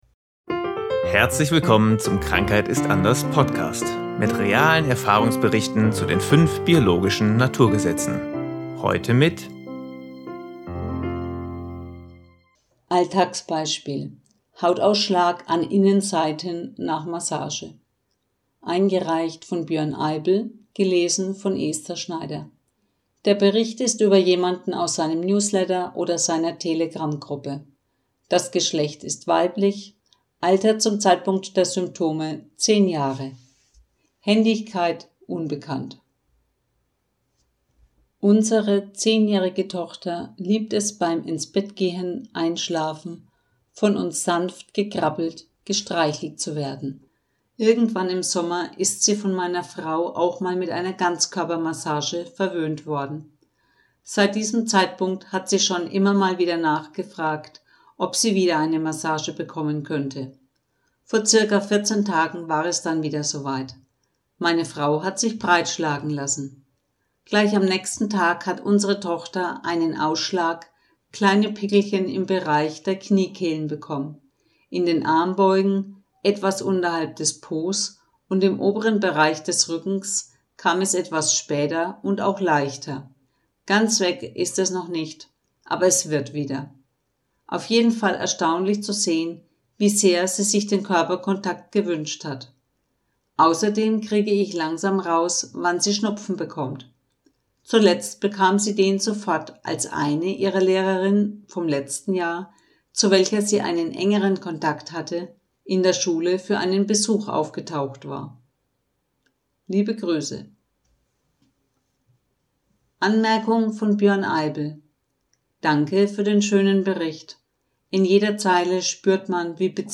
Bericht als Sprachaufnahme